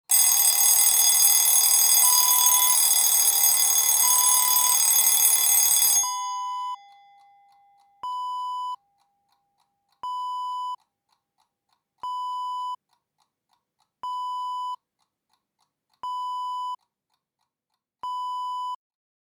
Alarm Clock Ringing Wav Sound Effect #4 - Tradebit
Description: Alarm clock ringing and ticking after being turned off
Properties: 48.000 kHz 24-bit Stereo
A beep sound is embedded in the audio preview file but it is not present in the high resolution downloadable wav file.
alarm-clock-preview-5.mp3